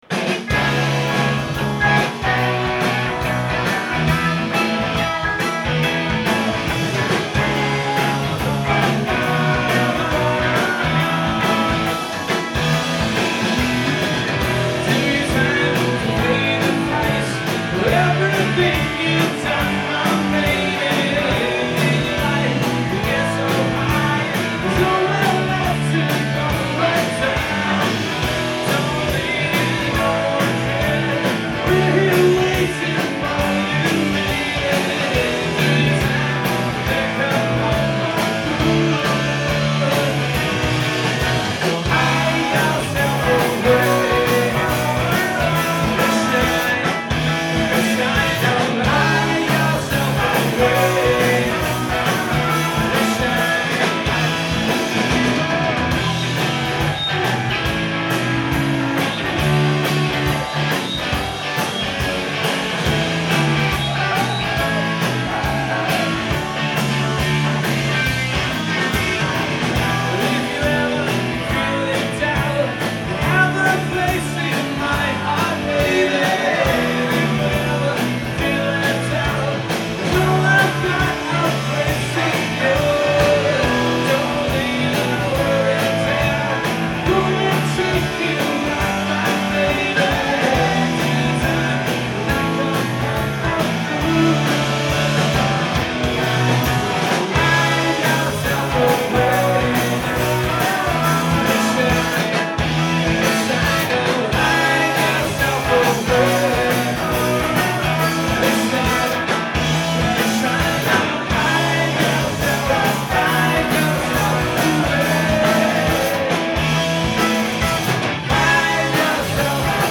Live at The Middle East Downstairs
in Cambridge, Massachusetts